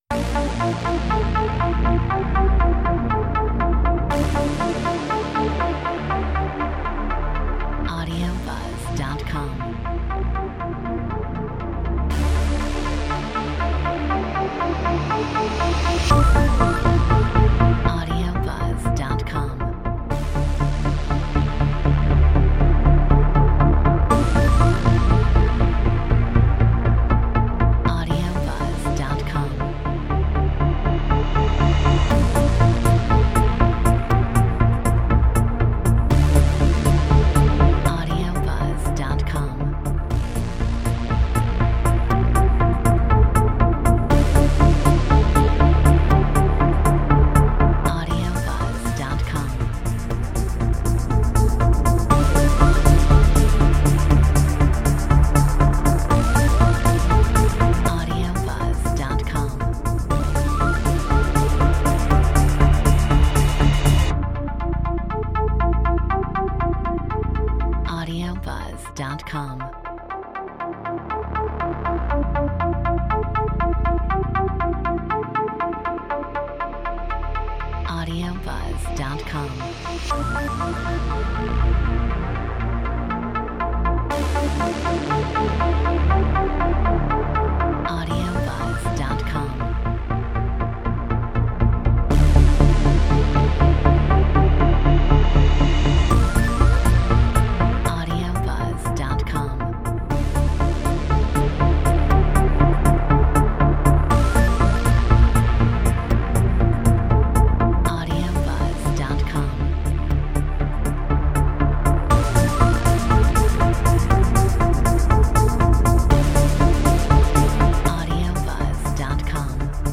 Metronome 120 BPM
Drum machine Synth bass Synth strings Synthesizer